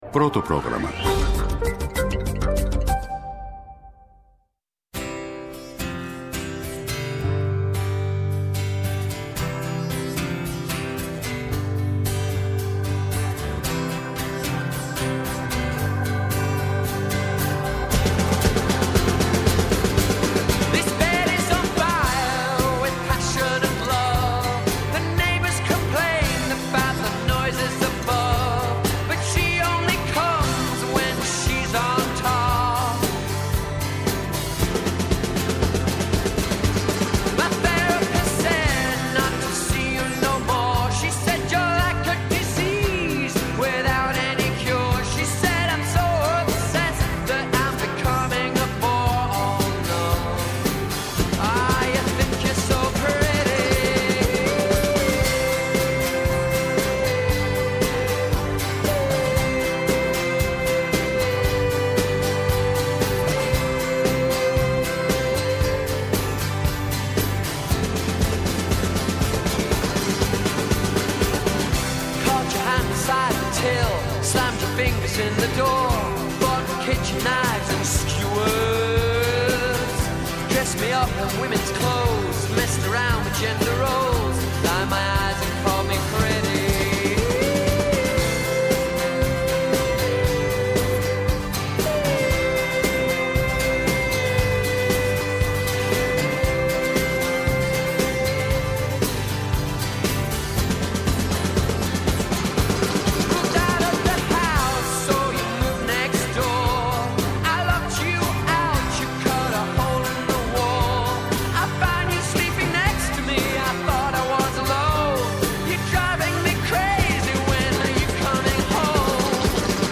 -Ο Κώστας Καραγκούνης, υφυπουργός Εργασίας
ΕΡΤNEWS RADIO